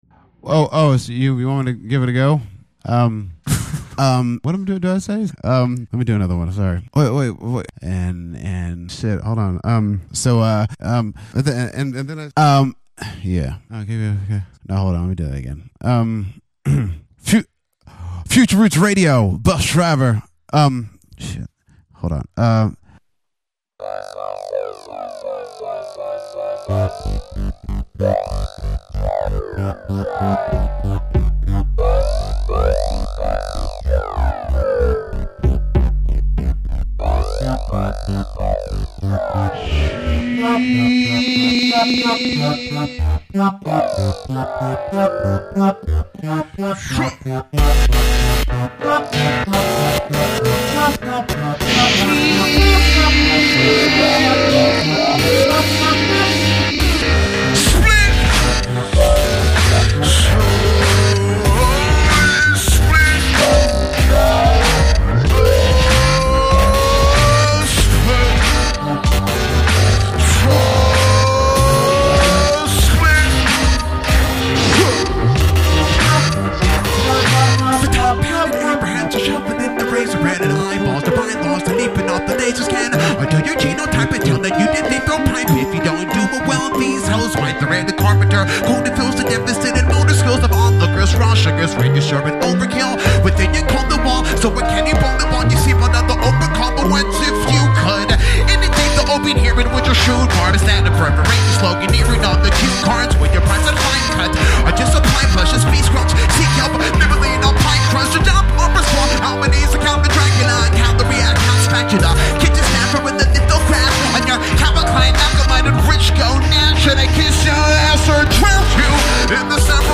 full blast live session
Funk/Soul Hip Hop